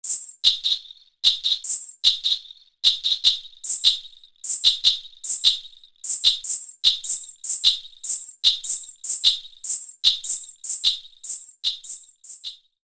Ghungru
The ghungru ( ghungroo ) is a pair of ankle bells that dancers wear when they perform classical dances. Due to the very rhythmic nature of their dances, it makes a sound at important points of the rhythm, or even replicate the rhythm.
gunghroo.mp3